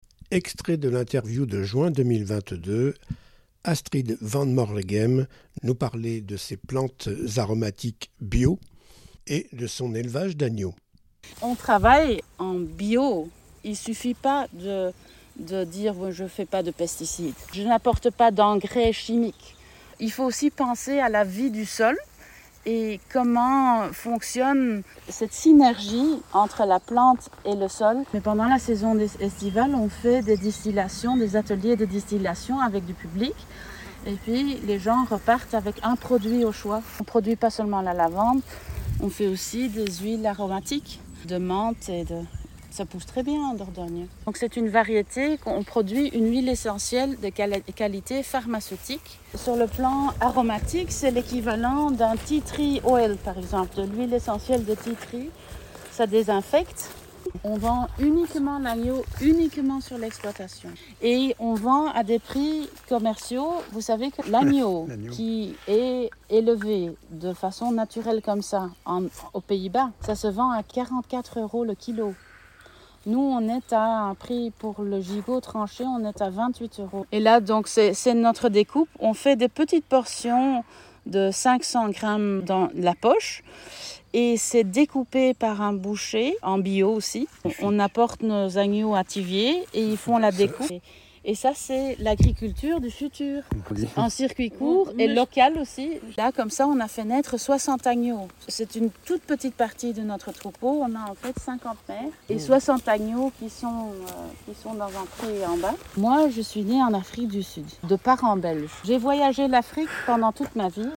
Archives. Extraits audio d’une interview